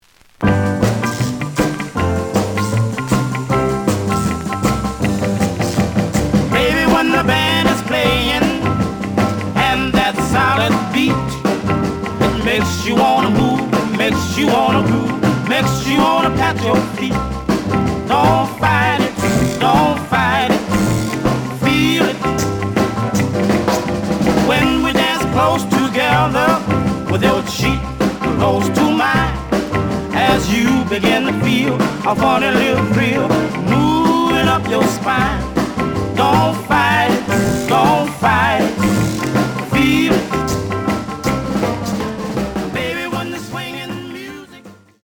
The audio sample is recorded from the actual item.
●Genre: Rhythm And Blues / Rock 'n' Roll
Slight click noise on 2 points of B side due to a bubble.